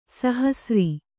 ♪ sahasri